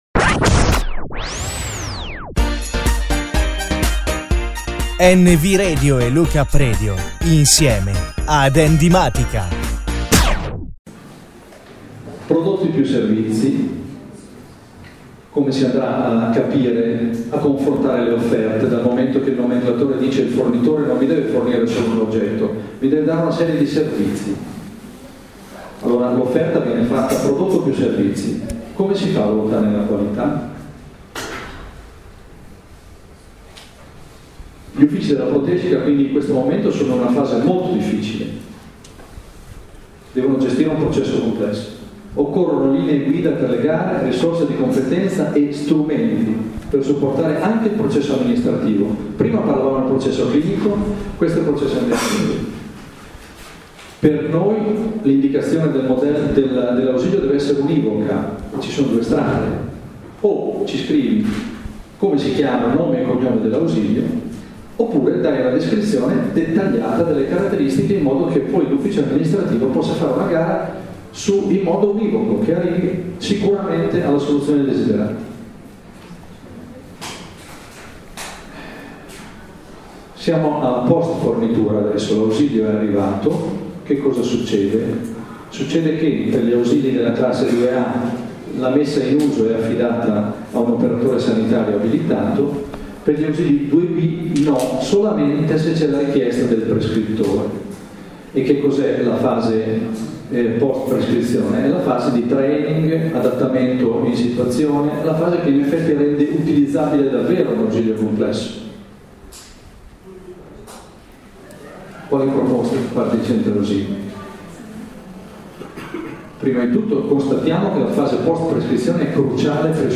Registrazione del convegno sul nomenclatore tariffario, parte seconda